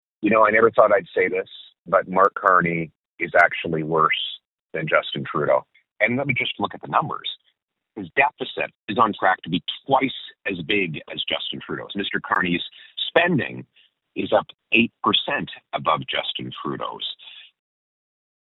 In an interview with Quinte News, Poilievre spoke about the transition from Prime Minister Justin Trudeau to Mark Carney.